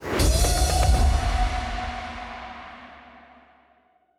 add_spin_popup.wav